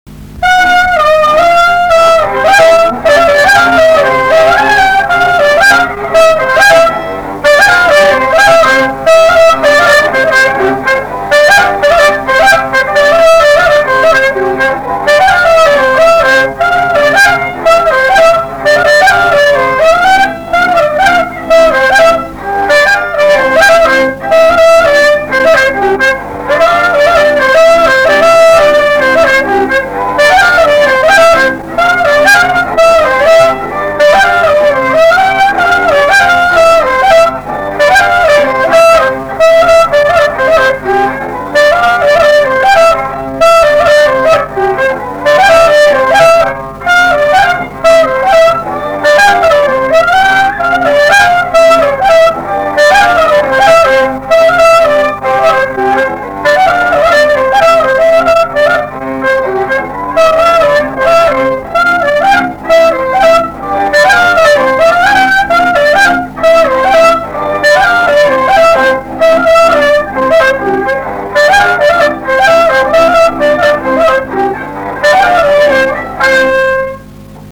šokis
Viečiūnai
vokalinis
Instrumentinė muzika
Klarnetas, smuikas, kontrabosas